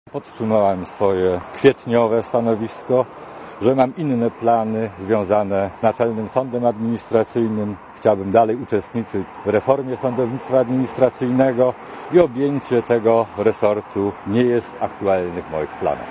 * Mówi profesor Hauser*